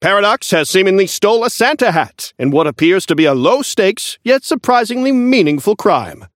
Newscaster voice line - Paradox has seemingly stolen a Santa hat, in what appears to be a low-stakes, yet surprisingly meaningful crime.
Newscaster_seasonal_chrono_unlock_01_alt_01.mp3